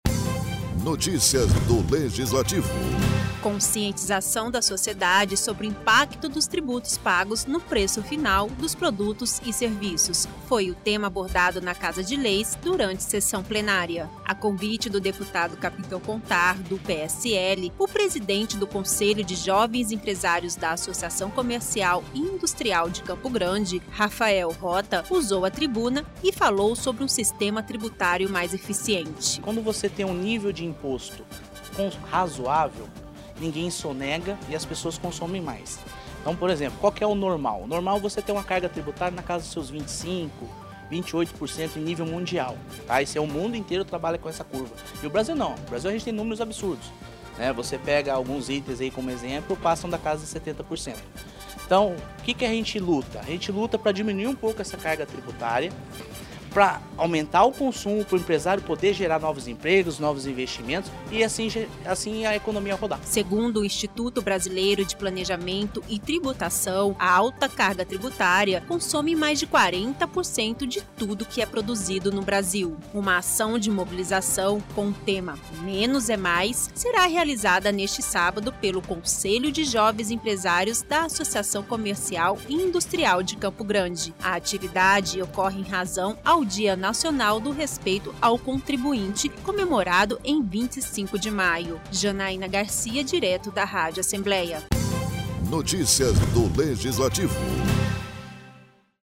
Conscientização de uma sociedade sobre o impacto dos tributos pagos no preço final dos produtos e serviços foi tema abordado na Assembleia em sessão plenária.